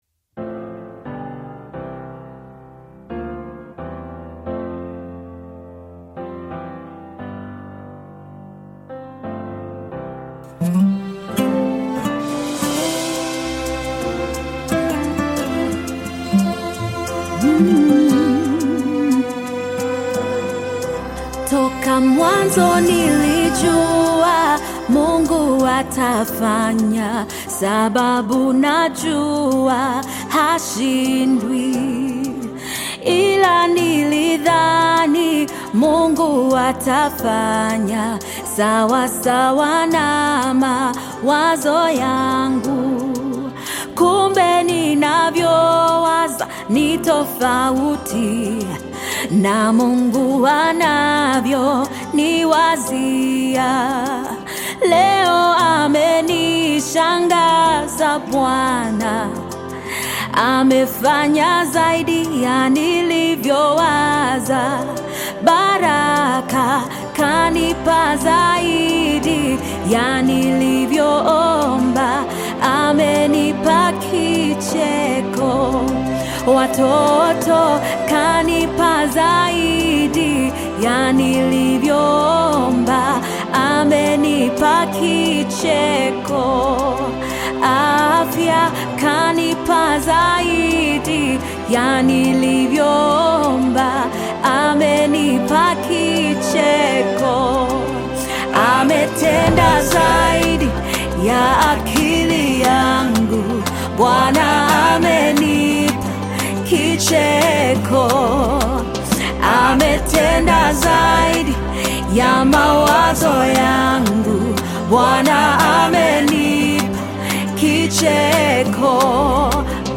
gospel song
African Music